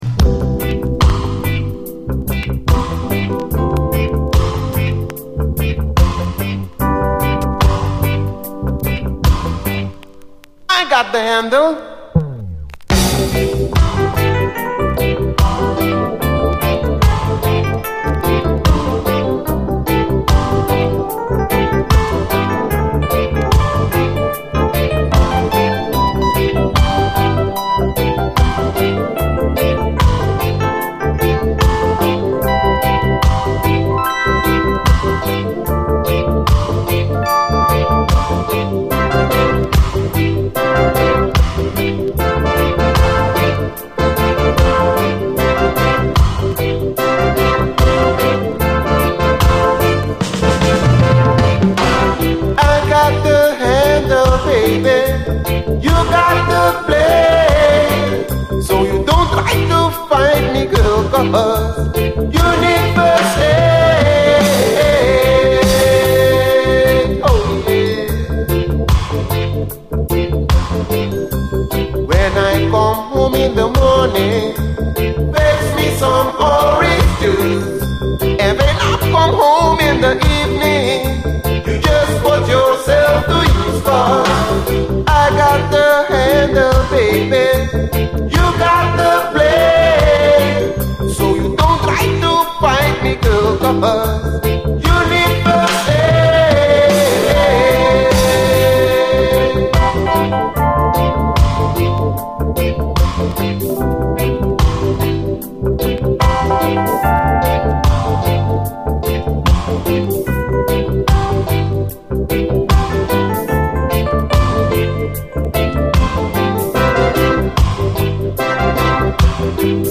REGGAE, 7INCH
UKルーツ好きにオススメしたい、洗練されたコーラス・ワークもビューティフルな素晴らしい一曲！